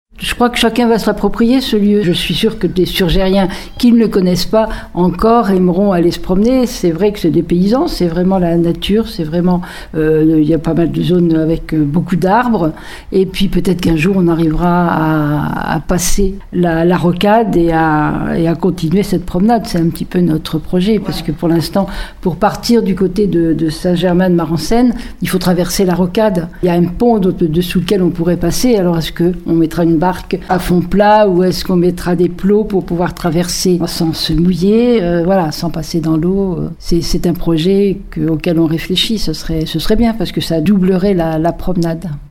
Et la maire de Surgères Catherine Desprez veut aller encore plus loin pour allonger le parcours :